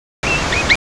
A Scream of Alarm Calls
Vancouver Island marmot
vantrill.aiff